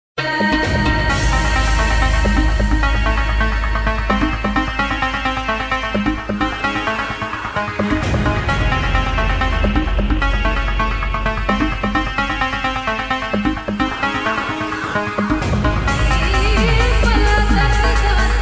Wonderful arabic tune